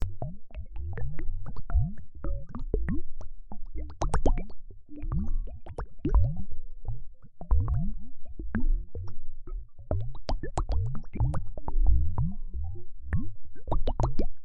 Free SFX sound effect: Wizzard Bubbles.